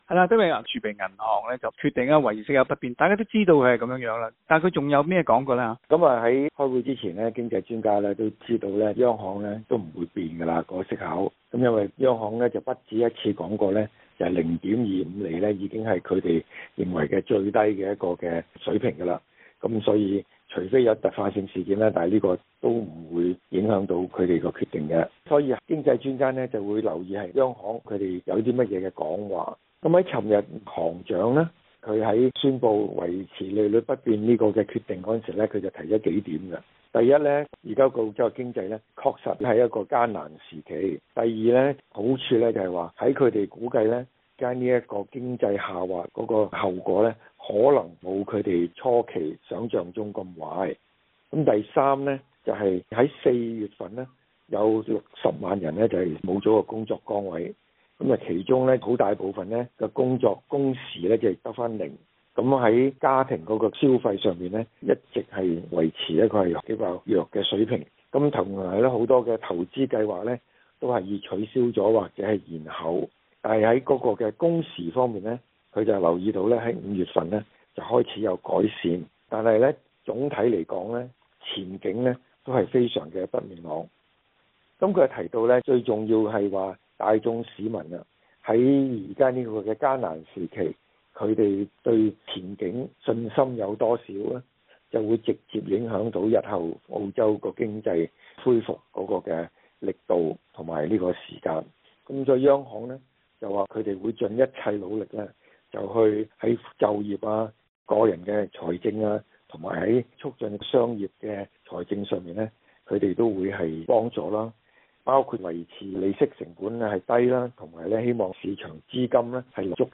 箇中情况请收听今日的访问。